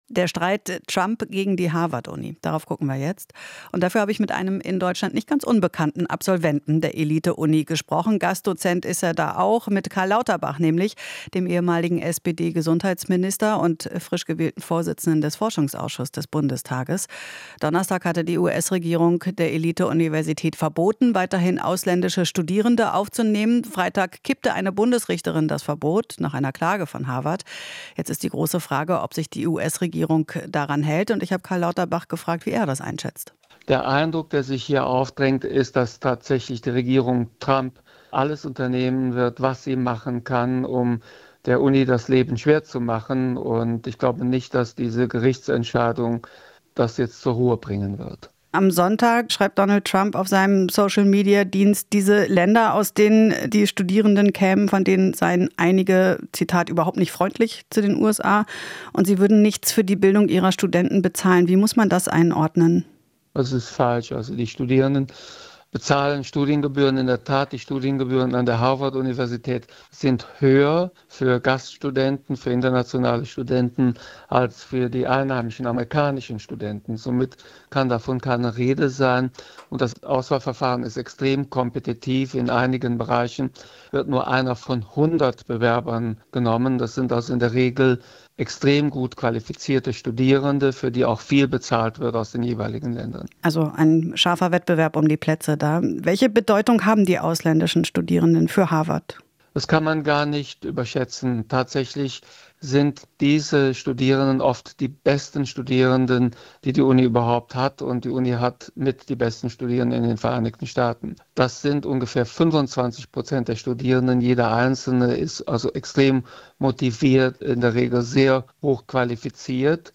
Interview - Lauterbach (SPD) zu Harvard gegen Trump: "Wichtiger symbolischer Kampf"